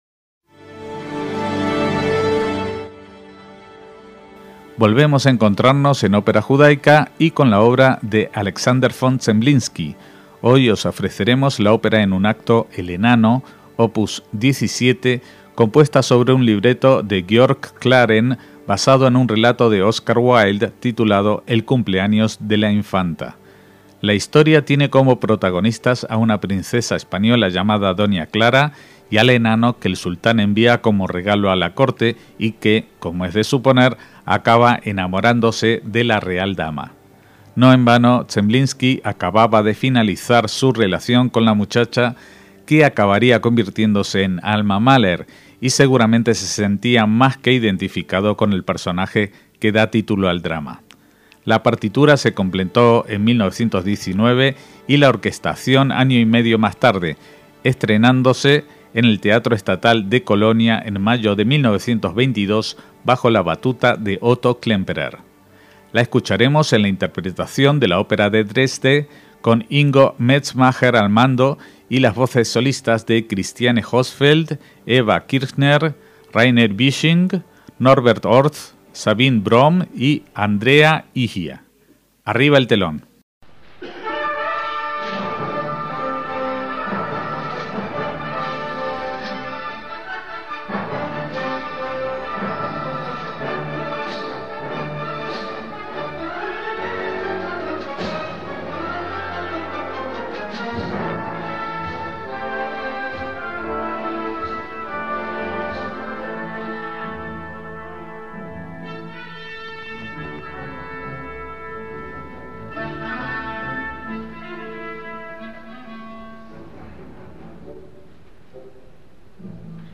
ópera en un acto
voces solistas